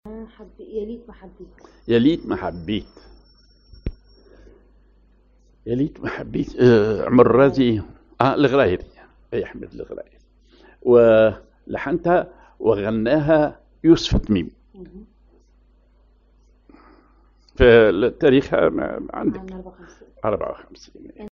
Maqam ar الراست سازكار
Rhythm ar الوحدة
genre أغنية